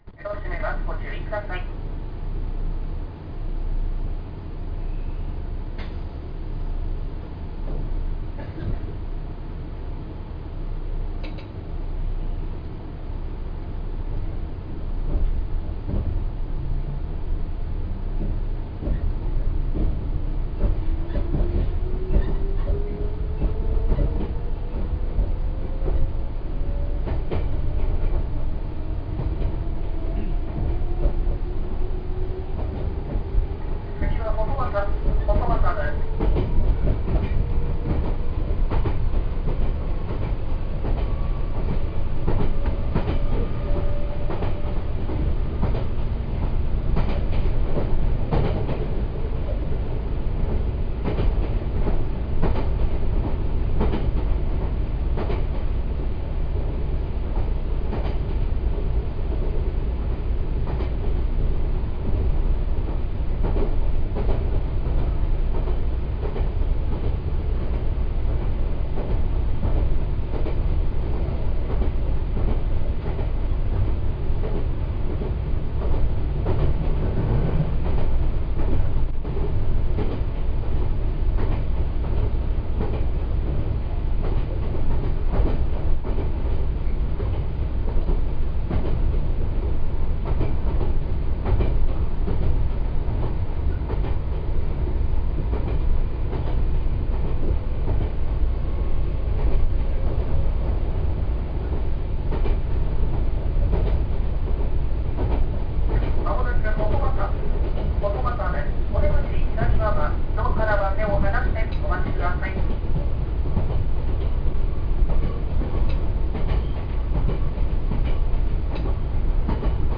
・5300系走行音
ただ、随分と渋い音で、あまりチョッパらしくないような気も…。